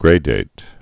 (grādāt)